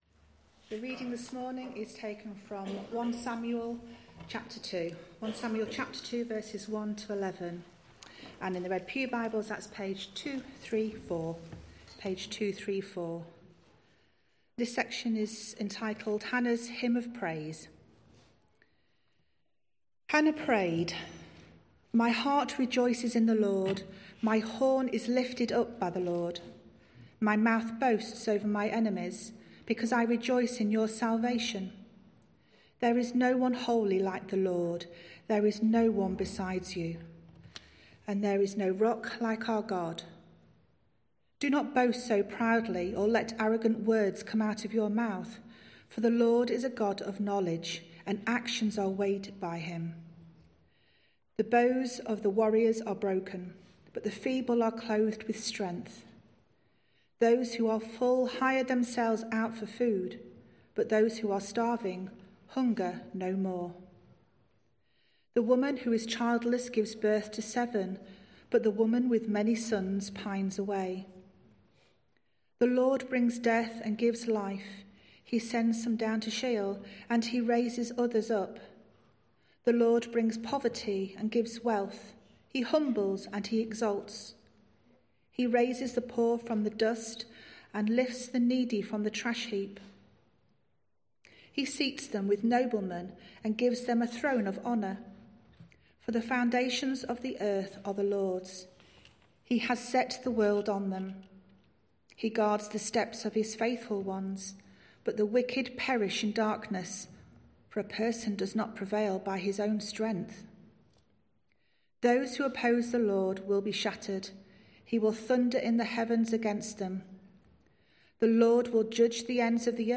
preaches through the book of 1 Samuel, starting in March 2026